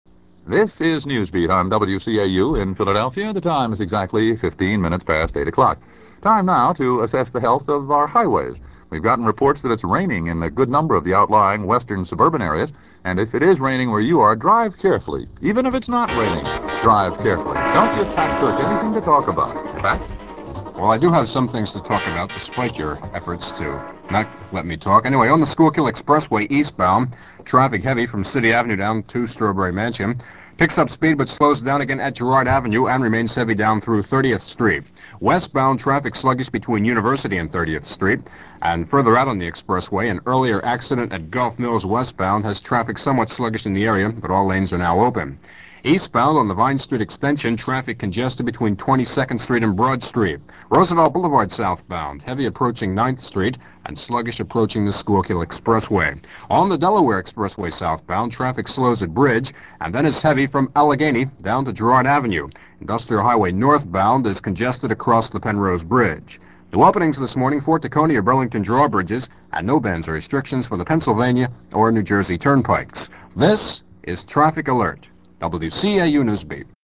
The following clips are from an October 15, 1970 aircheck of WCAU-AM's Morning NewsBeat program, which was all news from 6 to 9 a.m.